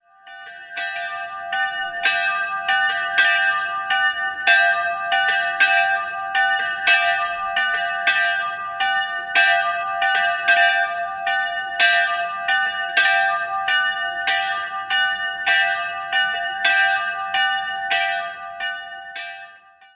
Jahrhundert erfolgten weitere Veränderungen, so beispielsweise die Errichtung des barocken Hochaltars. 2-stimmiges Geläute: e'''-fis''' Die beiden Glocken wurden Anfang des 16. Jahrhunderts in Mühldorf gegossen.